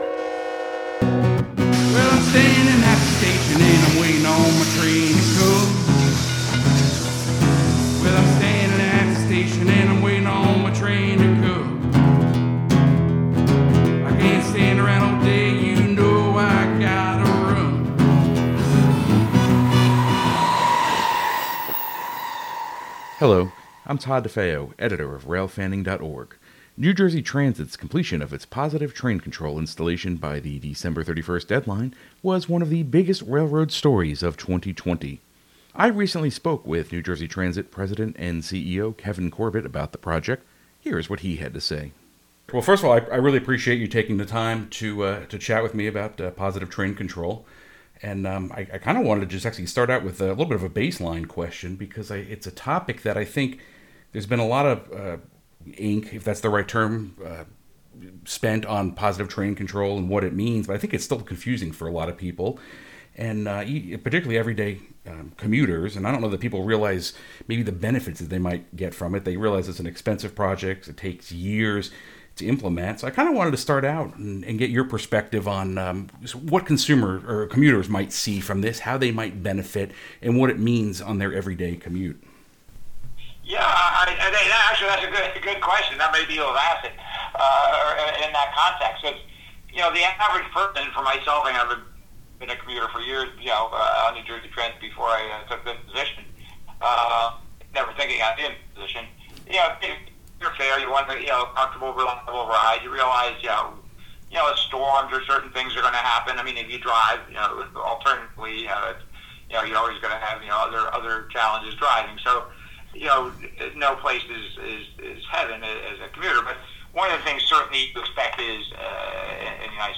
I recently spoke with New Jersey Transit President & CEO Kevin Corbett.
Sound Effects Diesel Horn: Recorded at the Southeastern Railway Museum on Nov. 14, 2020. Steam Train: 1880s Train, recorded Sept. 12, 2020, in Hill City, South Dakota. Show Notes This conversation has been edited slightly to remove some brief moments of silence and snippets of dialogue for the sake of clarity.